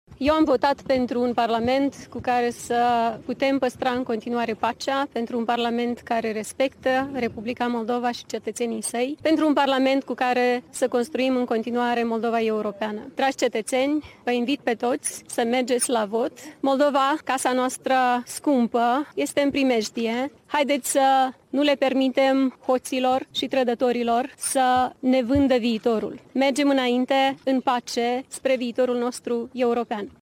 După ce a și-a exprimat opțiunea la urne, preşedinta Maia Sandu a îndemnat electoratul Republicii Moldova să iasă la vot.